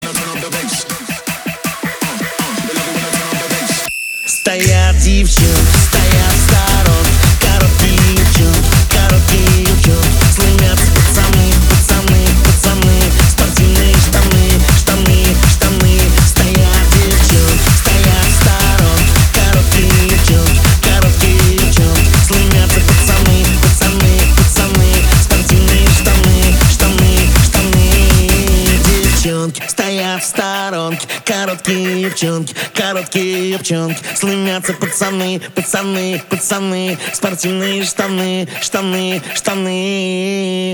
громкие
Драйвовые
веселые
энергичные
быстрые
свисток
Hard dance
рейв
лай собаки